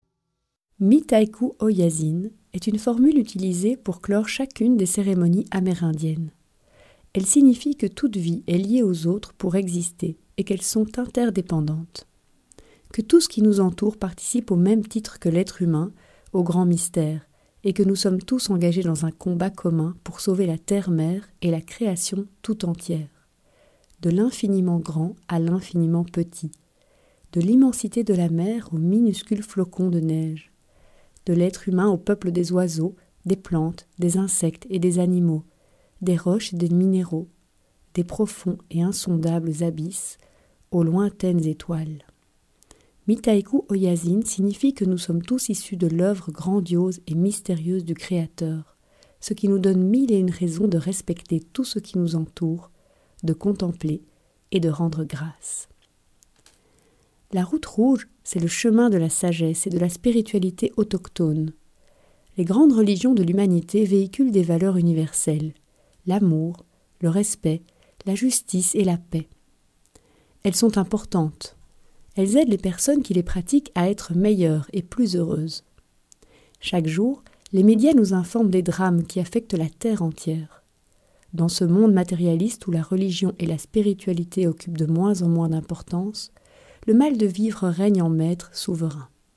Click for an excerpt - Étincelles de sagesse amérindienne de Claire Payment